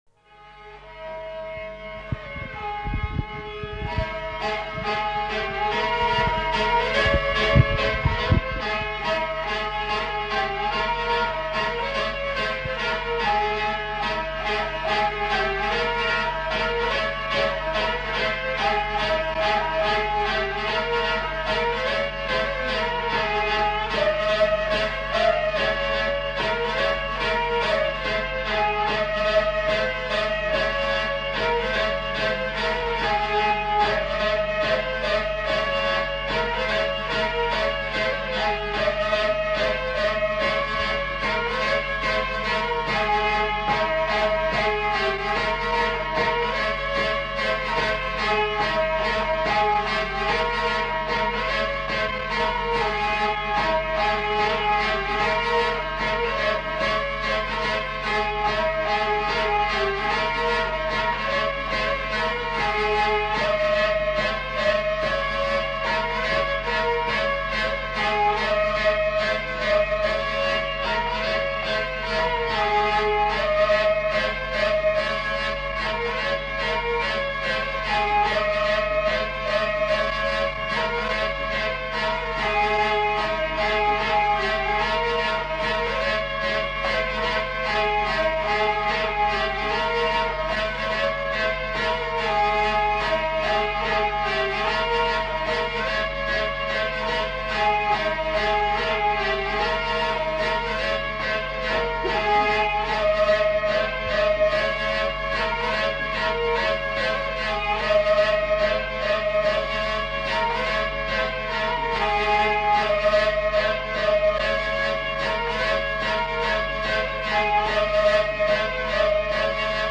Mazurka
Aire culturelle : Gabardan
Lieu : Mauléon-d'Armagnac
Genre : morceau instrumental
Instrument de musique : vielle à roue
Danse : mazurka